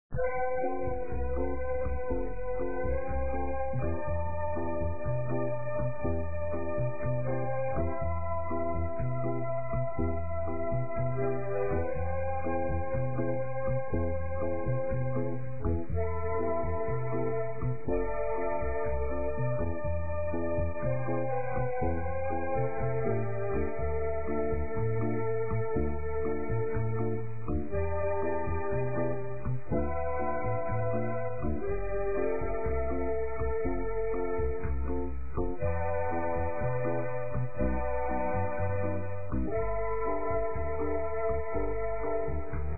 Essa gravação foi feita em  1999, no Rio, durante a criação da obra.
A novidade não está no rítimo ( a Future Music usa todos os gêneros conhecidos, principalmente brasileiros!), mas consiste em um novo tipo de melodia e harmonia, suave, tranquila e totalmente intuitiva. Feita com recursos eletrônicos, mas ainda instrumentos convencvionais e novos.
O artista cria a música na hora da execução, nunca lê uma partitura.